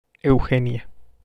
^ Estación del Metro Eugenia. Spanish pronunciation: [euˈxenja]
Es-mx-Eugenia.wav.mp3